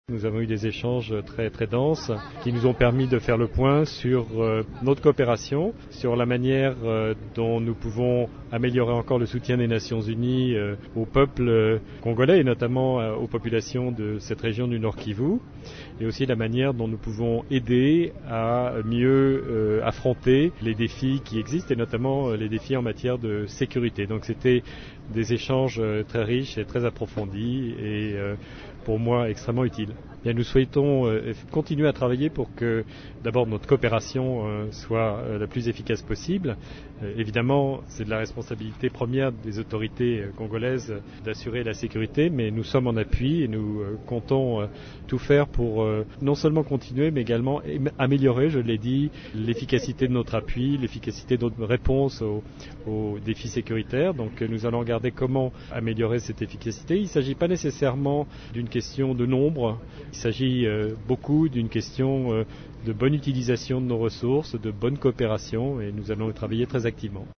Au cours d’une conférence de presse sur le tarmac de l’aéroport de Goma, Jean Pierre Lacroix a rapporté que des échanges qu’il a eus avec les autorités provinciales, les humanitaires ainsi que les différentes composantes de la MONUSCO ont porté sur l’évaluation de la situation sécuritaire et la collaboration entre la mission onusienne et les autorités provinciales.